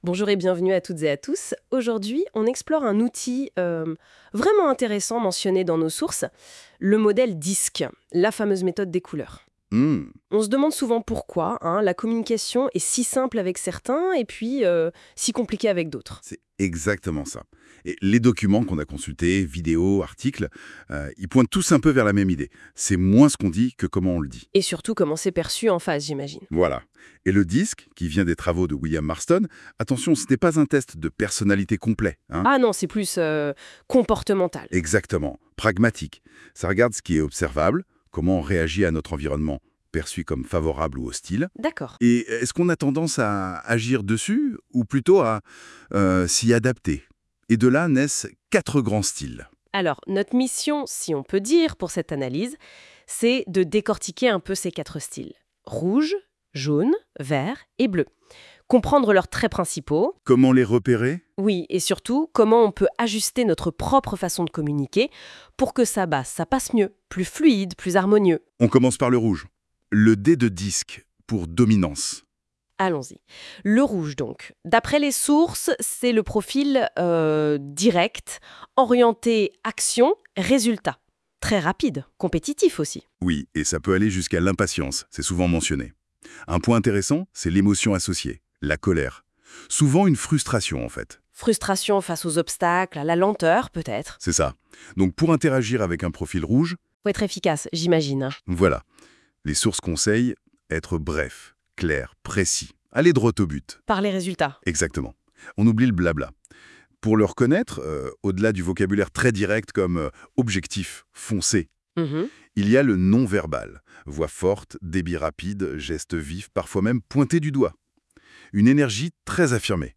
Il a été créé à partir de l’outil Google NotebookLM.